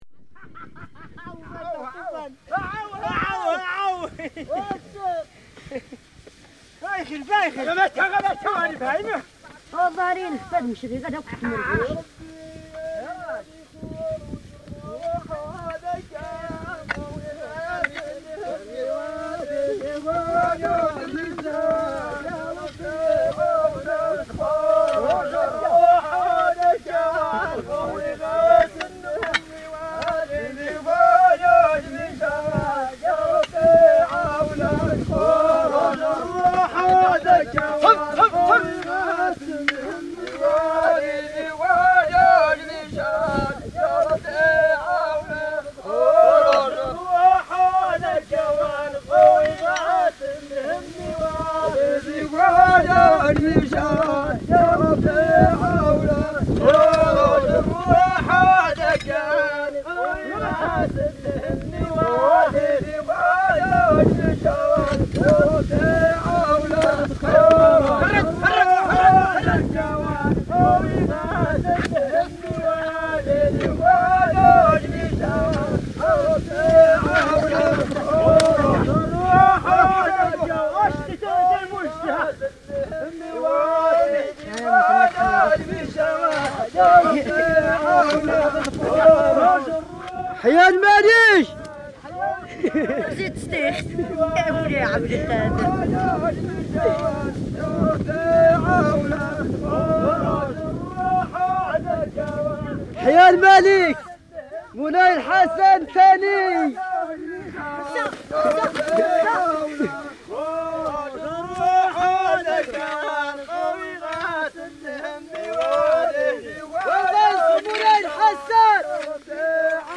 reel-to-reel tape recordings of Berber (Ait Haddidu) music and soundscapes
in 1961